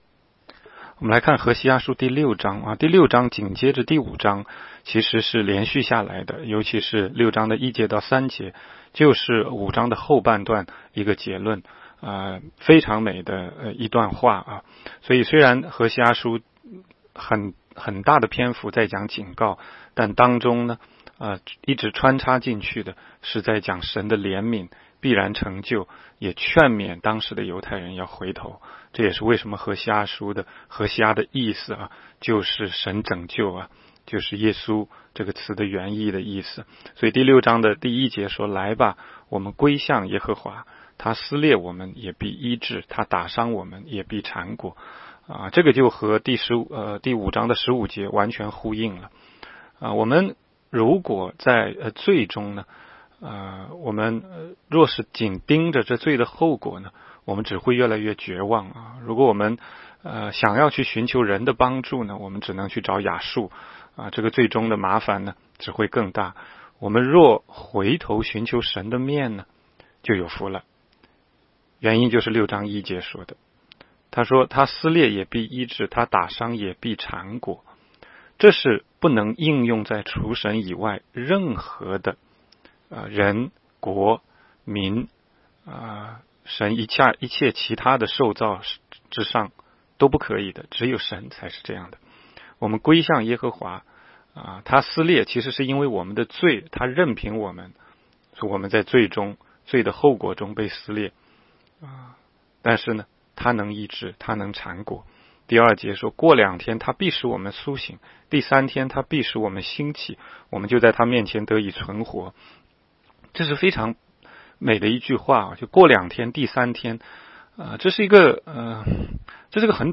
16街讲道录音 - 每日读经 -《何西阿书》6章